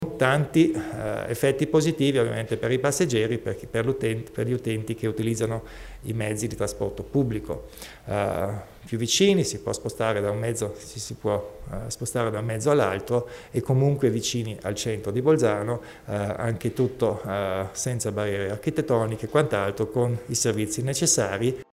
Il Presidente Kompatscher spiega i vantaggi dello spostamento della stazione degli autobus di Bolzano